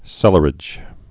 (sĕlər-ĭj)